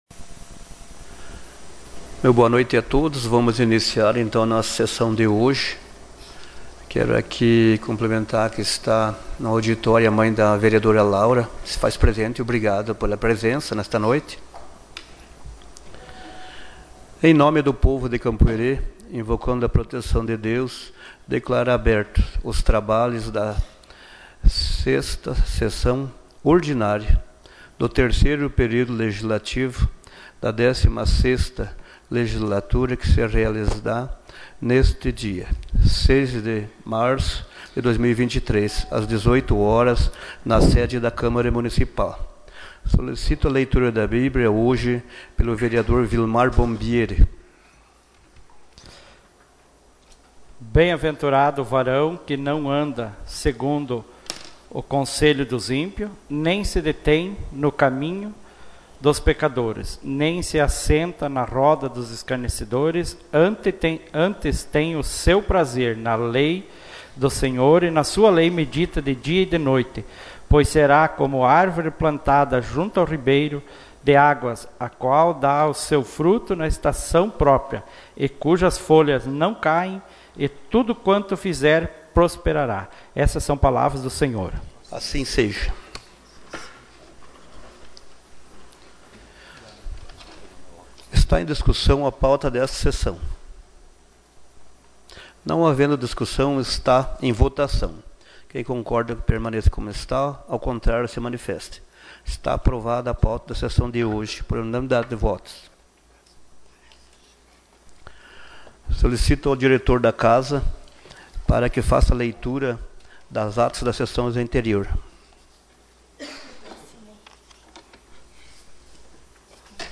Gravação das Sessões